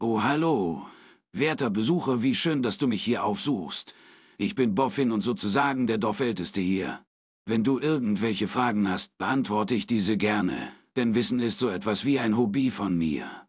boffin-kapitel-0-tts.mp3